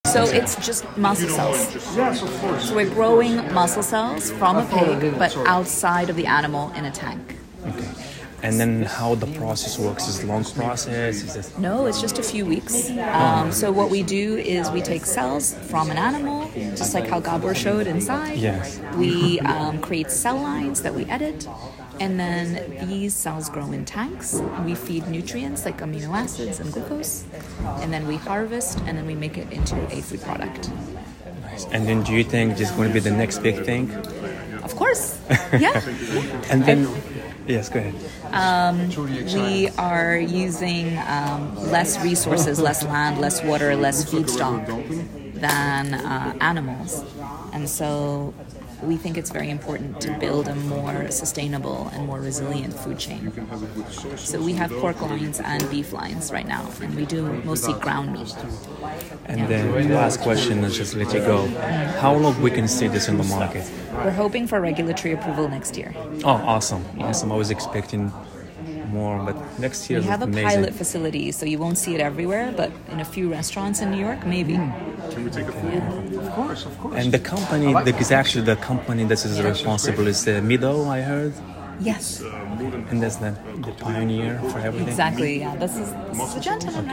Inside the Future of Food: My Experience at the FoodNiche Summit in NYC
Cultivated-Meat-Interview-.m4a